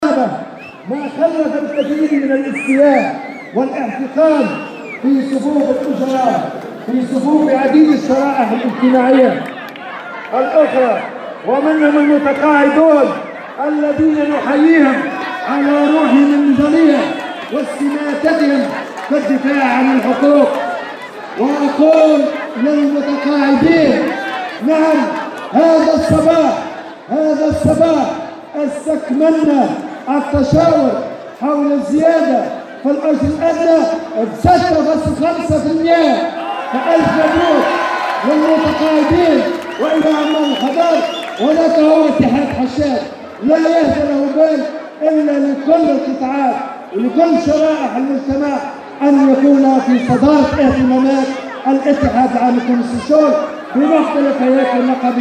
هنأ اليوم الأربعاء الأمين العام للاتحاد العام التونسي للشغل نور الدين الطبوبي، المتقاعدين بالقطاع الخاص بقرار حكومي صدر اليوم حول الزيادة في جراياتهم بالإضافة الى الزيادة في أجر العاملين بالحضائر، وذلك في كلمة القاها اليوم أمام تجمع عمالي بمناسبة اليوم العالمي للعمال.